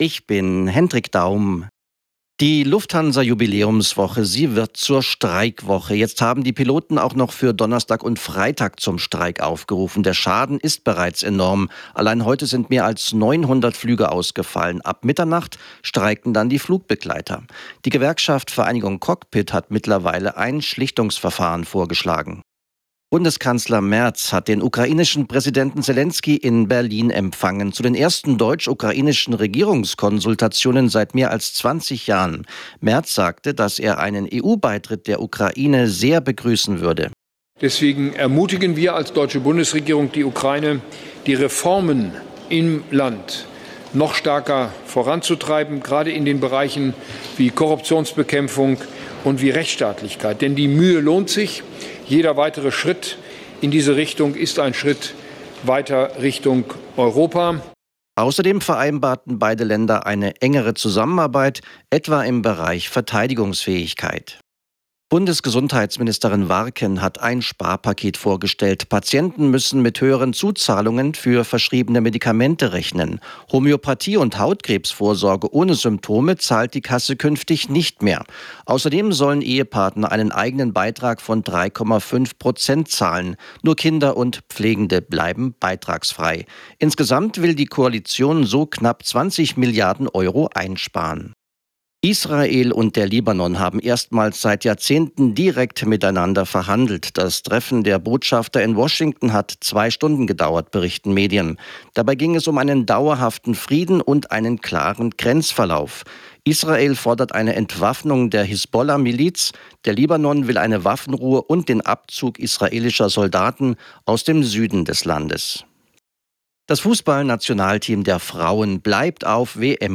Nachrichten , Nachrichten & Politik
Die aktuellen Nachrichten zum Nachhören